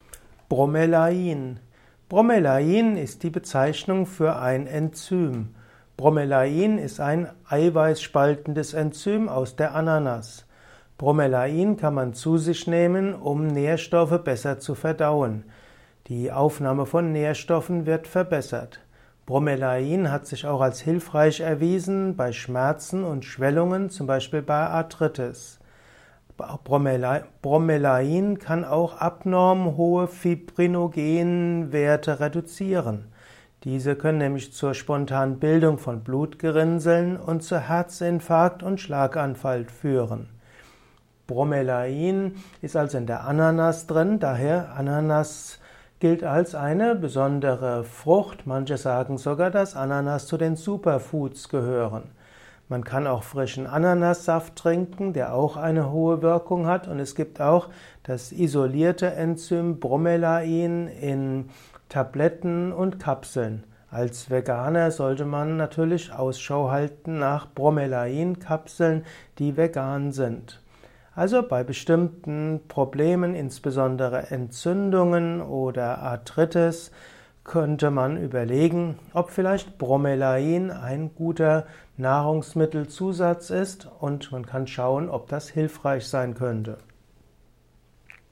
Was ist Bromelain? Erfahre es in diesem Kurzvortrag